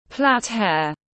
Plait hair /plæt heər/